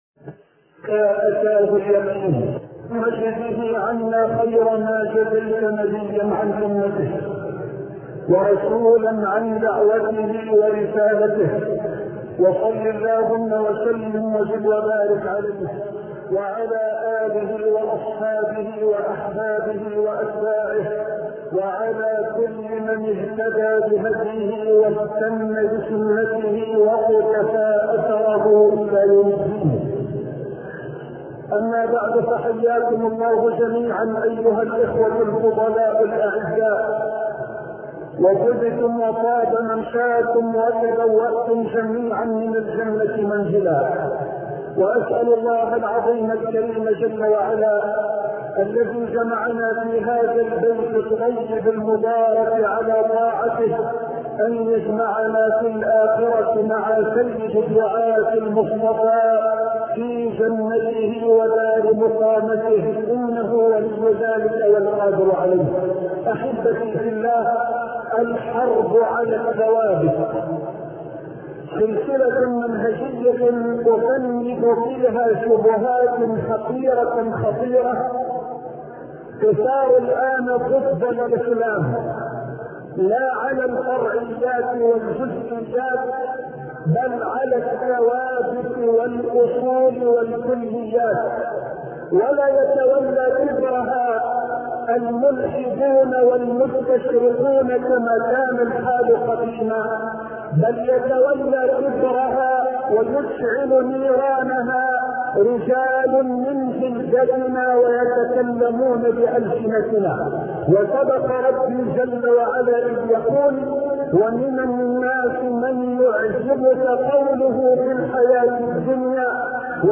شبكة المعرفة الإسلامية | الدروس | الحرب على الثوابت [2] |محمد حسان